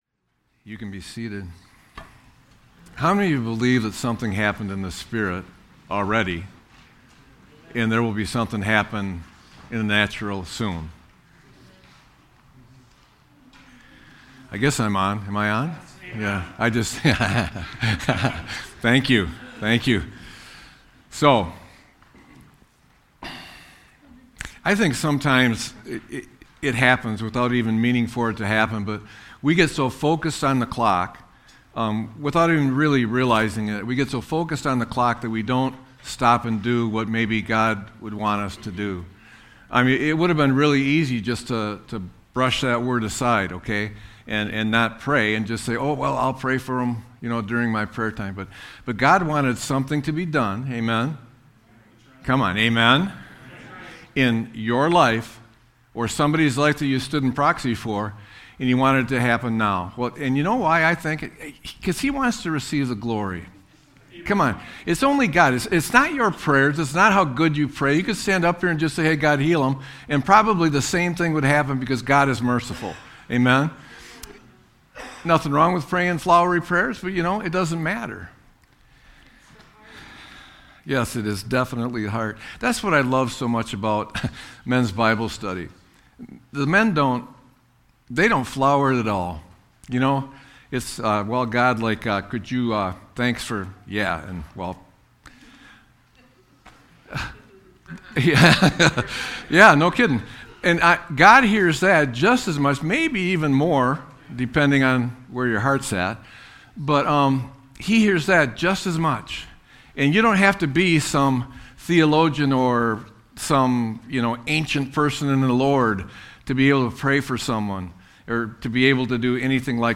Sermon-2-15-26.mp3